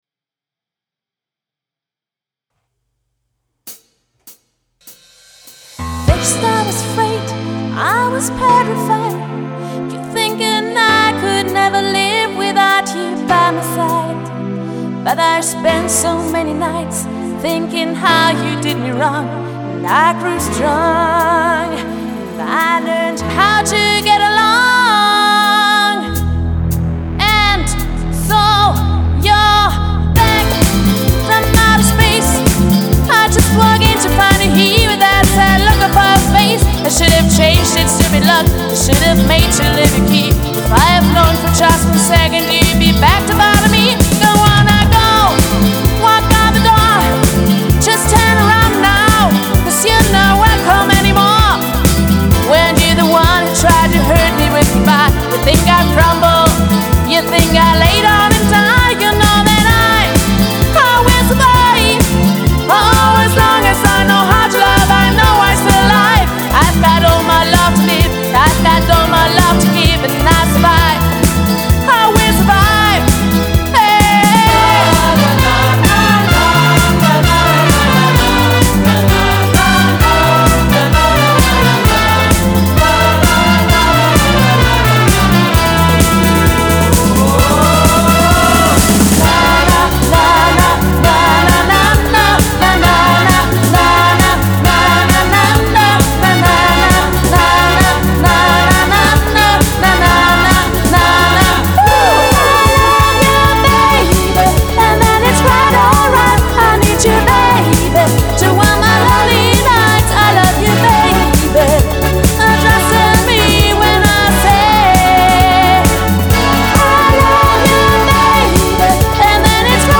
• Coverband
• Allround Partyband